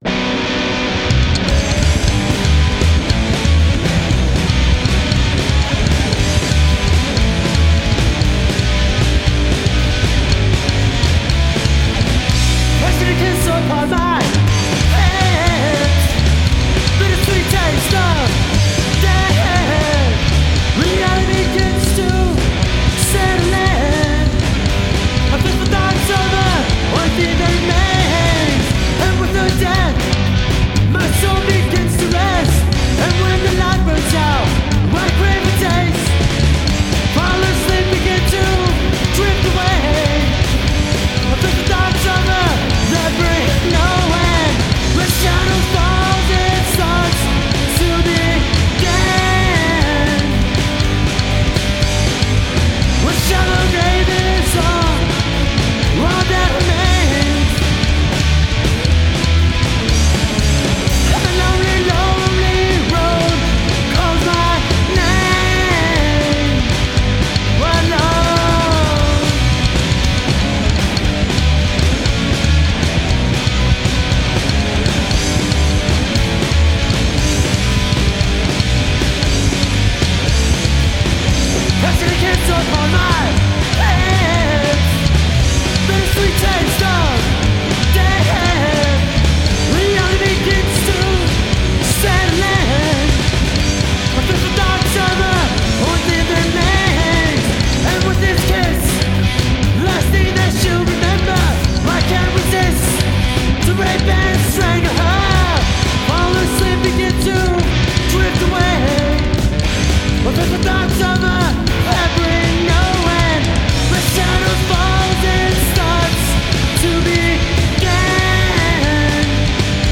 Just starting out and these boys rock.
Great Punk influenced Rockabilly.